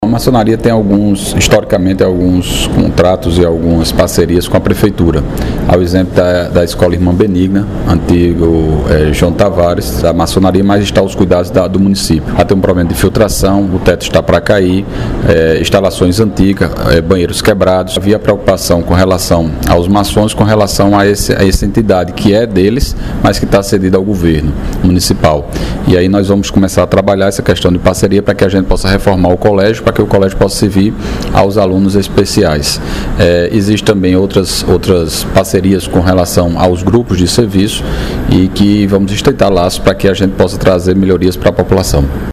Fala do Prefeito Dinaldinho Wanderley –